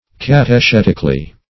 Search Result for " catechetically" : The Collaborative International Dictionary of English v.0.48: Catechetically \Cat`e*chet"ic*al*ly\, adv. In a catechetical manner; by question and answer.
catechetically.mp3